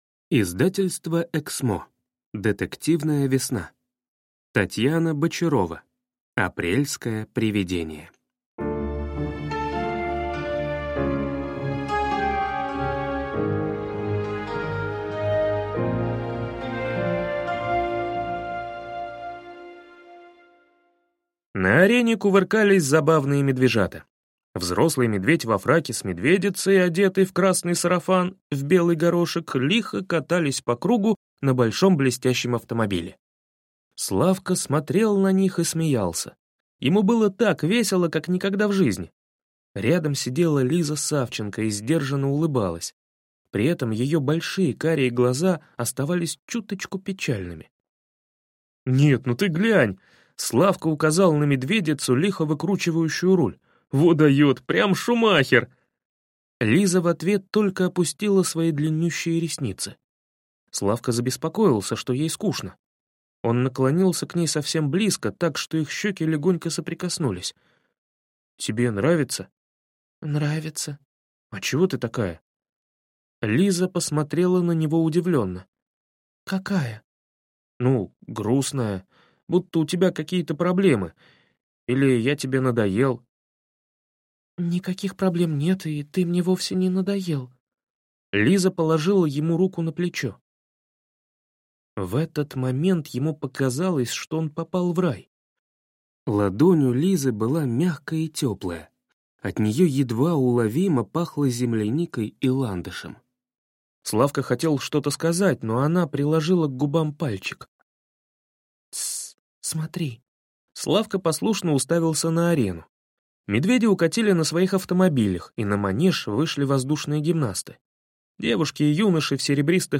Аудиокнига Детективная весна | Библиотека аудиокниг